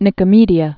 (nĭkə-mēdē-ə)